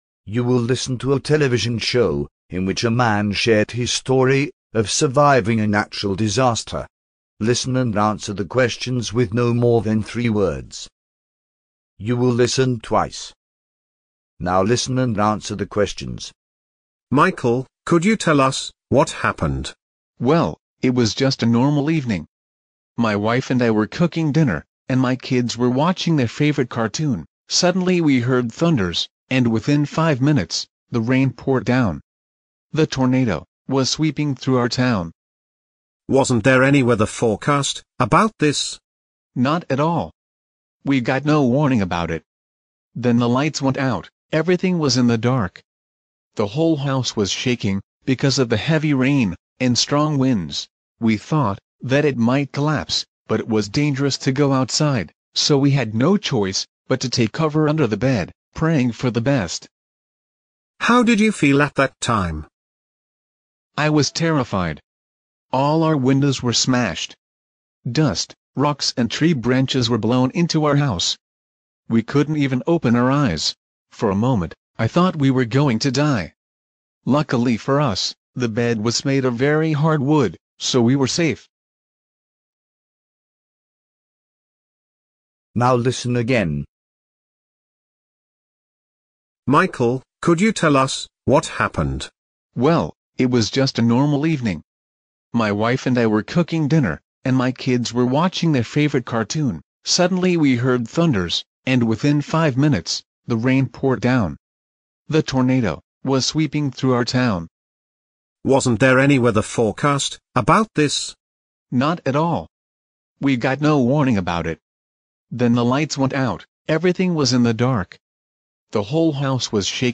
Listening test.mp3